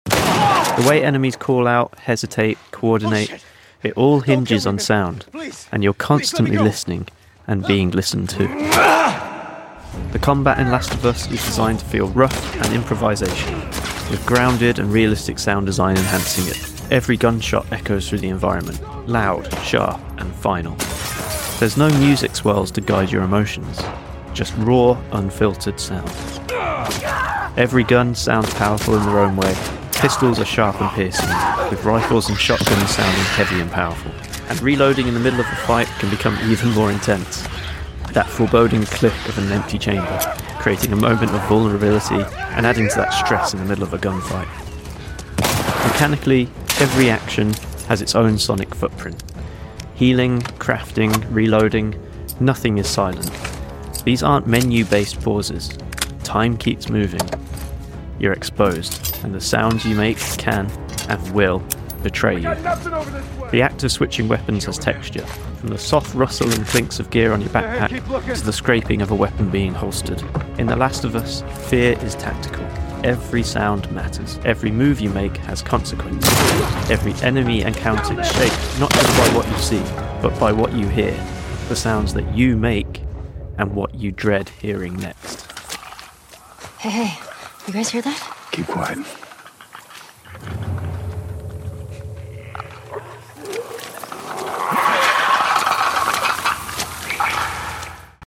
Combat in The Last of Us is designed to feel rough and improvisational, with grounded and realistic sound design enhancing it. Every gunshot echoes through the environment—loud, sharp, and final. There's no music swell to guide your emotions—just raw, unfiltered sound.
The act of switching weapons has texture, from the soft rustle and clinks of gear on your backpack to the scraping of a weapon being holstered.